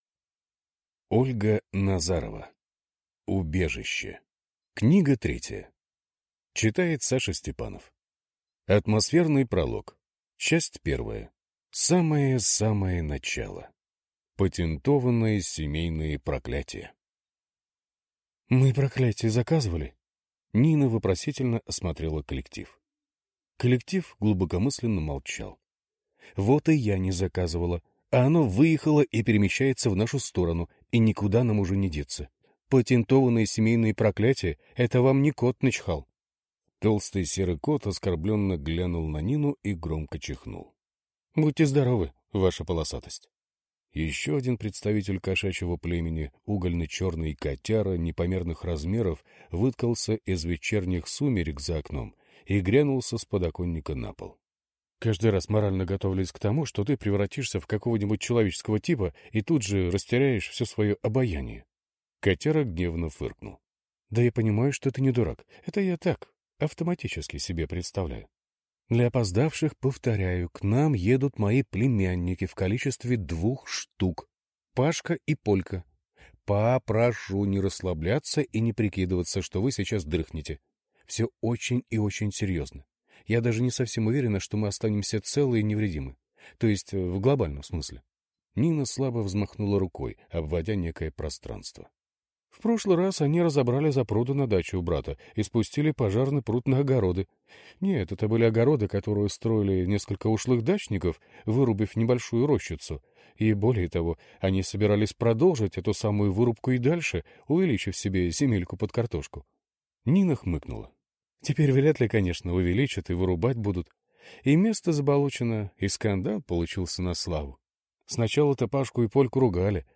Аудиокнига Убежище. Книга третья | Библиотека аудиокниг